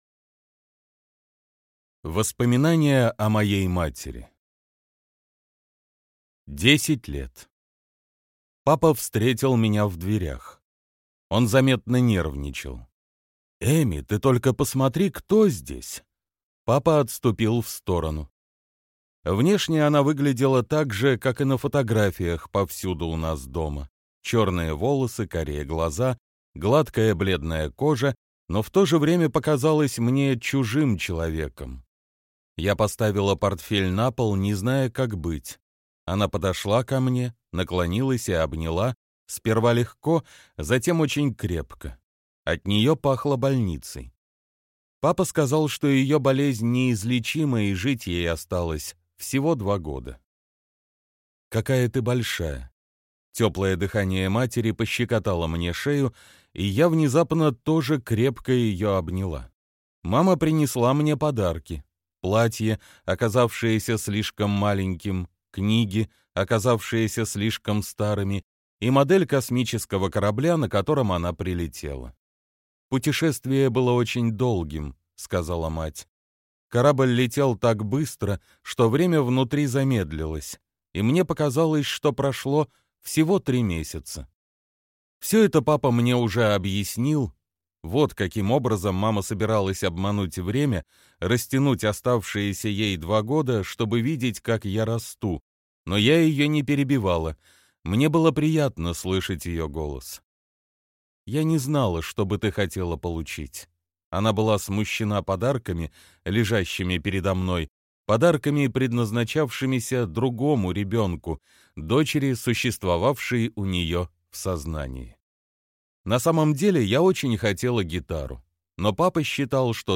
Аудиокнига Потаенная девушка | Библиотека аудиокниг